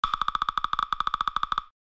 دانلود صدای دارکوب تکی از ساعد نیوز با لینک مستقیم و کیفیت بالا
جلوه های صوتی
برچسب: دانلود آهنگ های افکت صوتی انسان و موجودات زنده دانلود آلبوم صدای دارکوب نوک زدن از افکت صوتی انسان و موجودات زنده